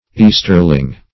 Easterling \East"er*ling\, n. [Cf. Sterling.]